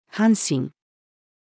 阪神-female.mp3